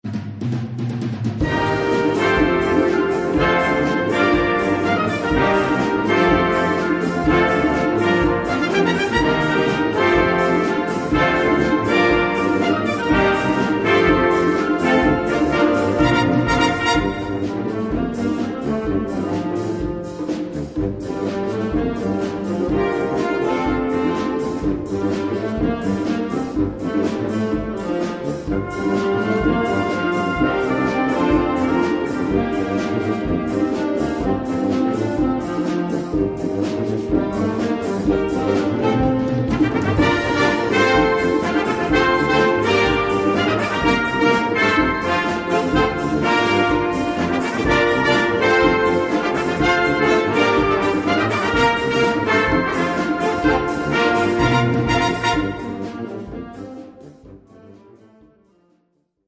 Gattung: Pophit
Besetzung: Blasorchester
Blasorchester